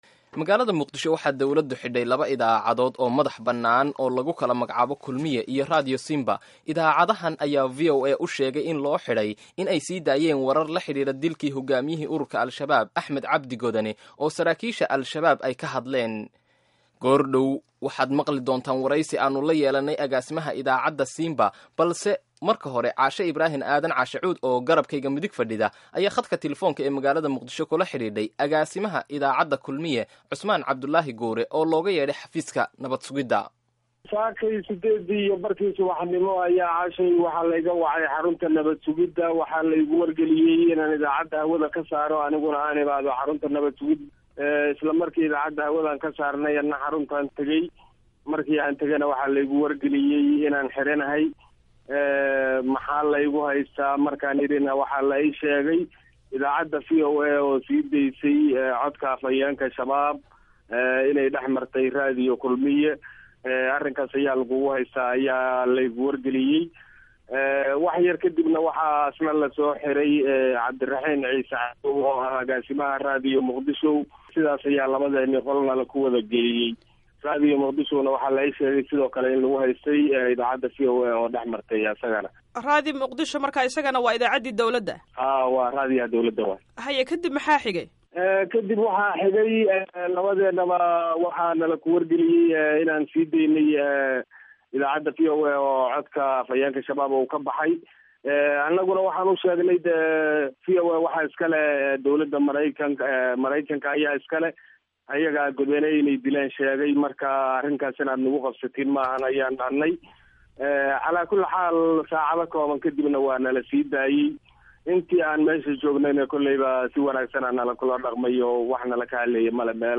Wareysiyada Kulmiye iyo Simba